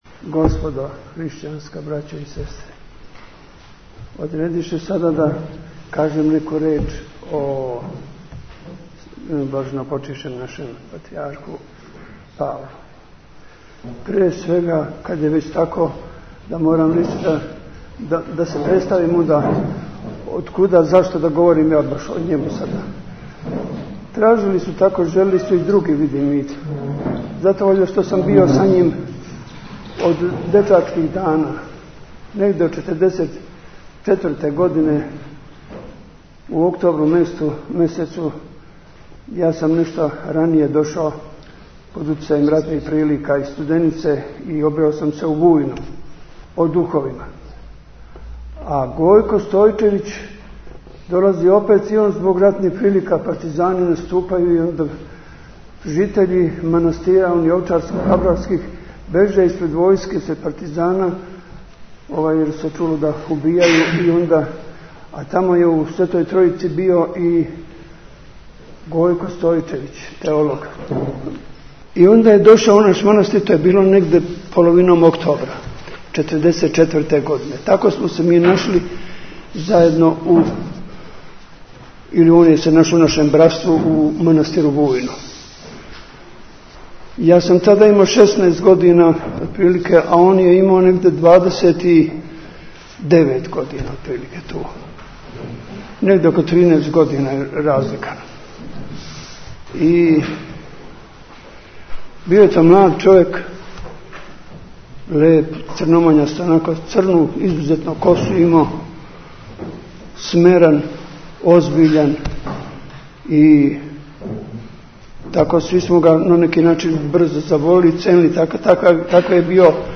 Beseda o patrijarhu Pavlu.mp3